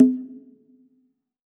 PCONGA HI.wav